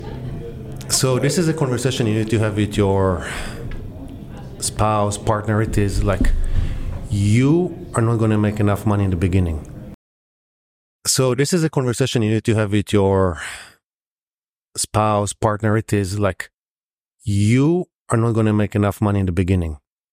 Podcasts with poor audio quality are hard to listen to, and these issues can be distractions for your listeners. Our podcast audio editing services are included in our post production work to ensure your podcast sounds professional and is free from distractions.
before-and-after-podcastable.mp3